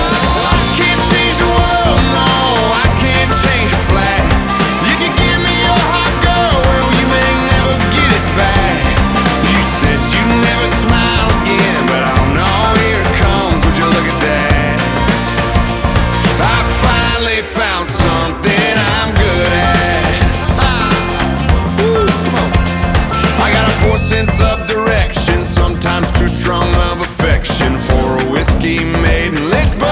• Country
His ad libs and laughter were left on the final recording.